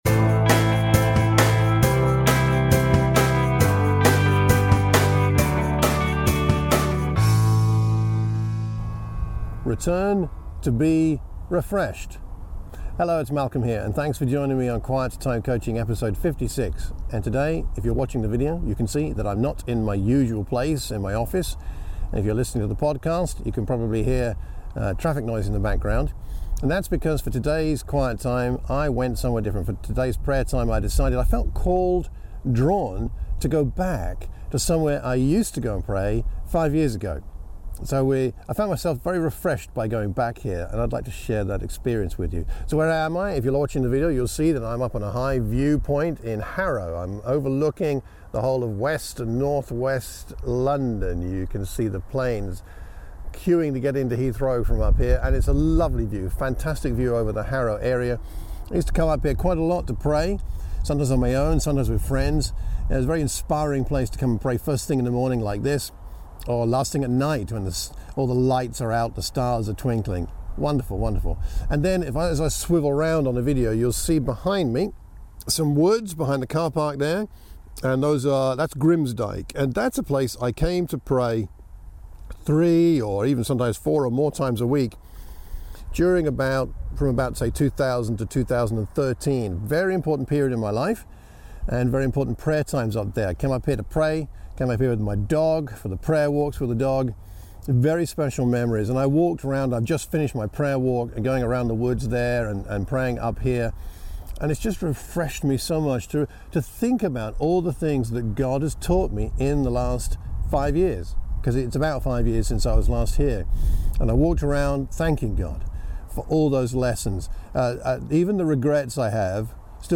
If you are watching the video you will notice that I am not my usual place to record today’s quiet time coaching episode.
I went to a viewpoint overlooking the Harrow area of north-west London.